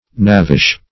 Knavish \Knav"ish\, a.